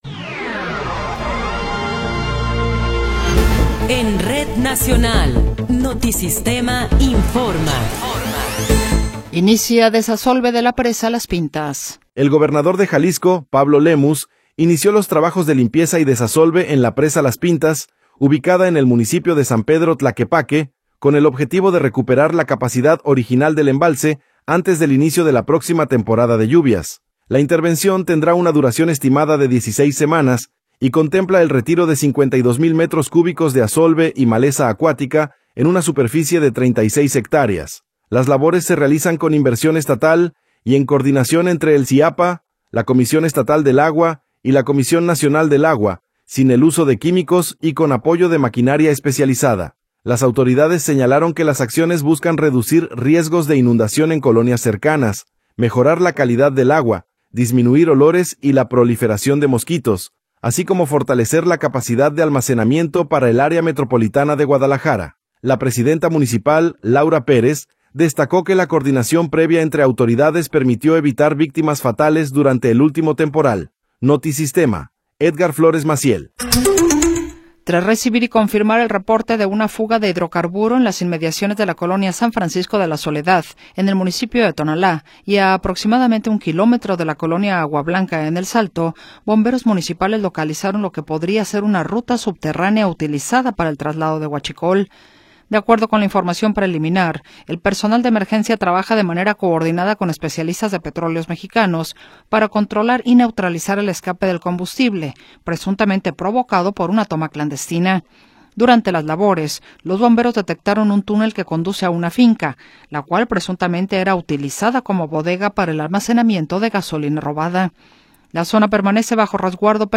Noticiero 17 hrs. – 13 de Enero de 2026
Resumen informativo Notisistema, la mejor y más completa información cada hora en la hora.